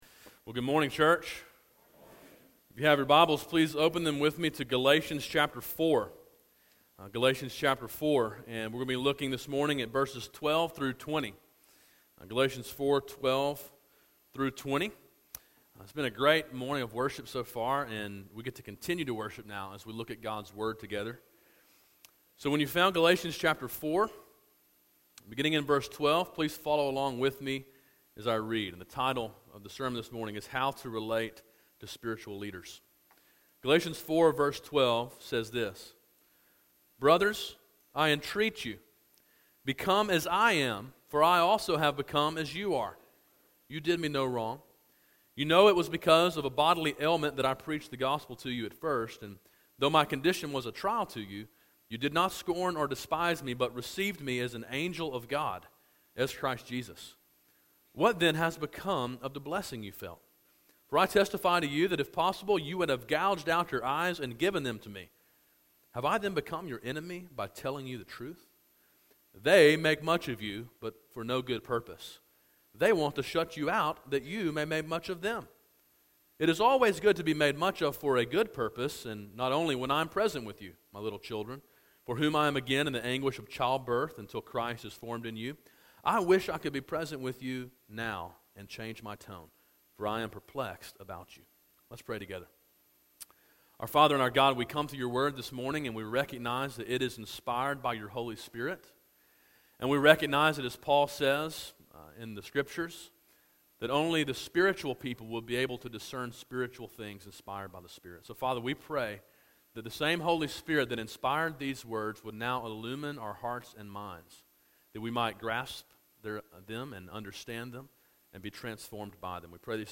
A sermon in a series titled Freedom: A Study of Galatians.